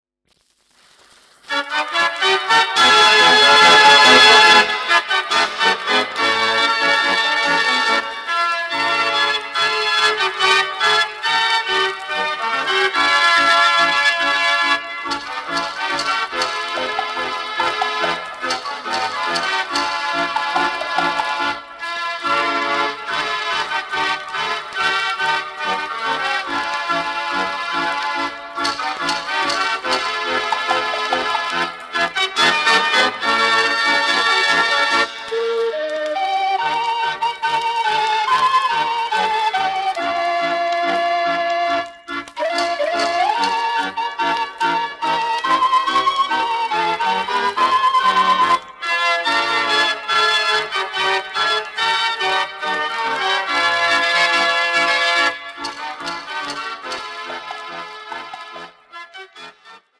Een afwisselende mix van Europees en Amerikaans repertoire.
Formaat 78 toerenplaat, 10 inch